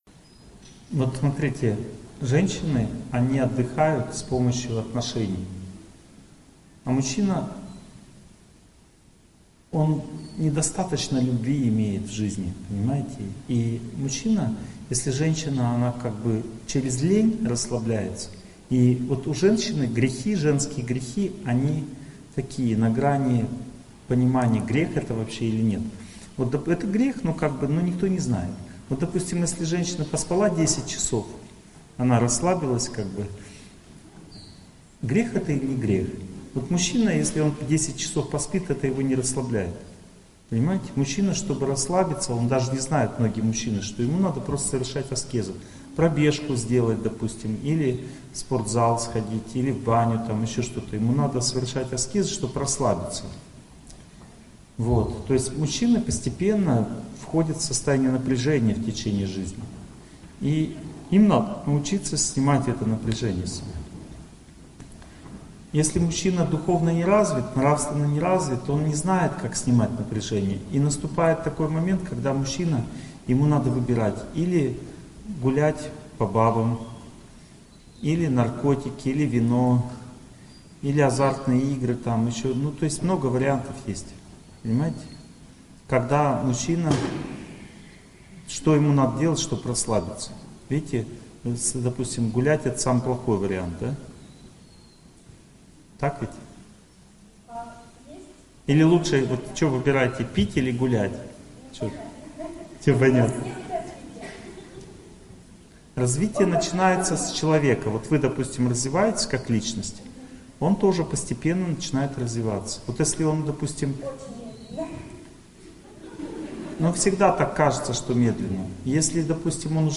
Аудиокнига Чувство собственного достоинства, судьба, успех. Часть 2 | Библиотека аудиокниг